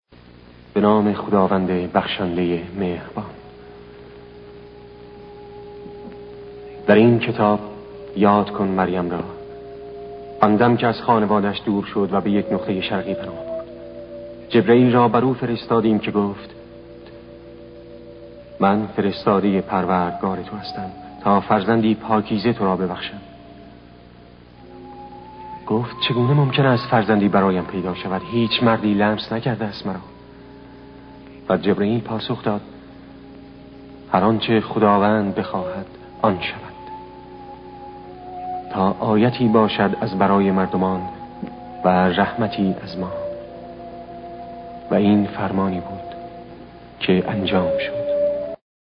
صدای جلال مقامی به جای نویل جیسون در نقش جعفر بن ابی‌طالب در فیلم محمد رسول الله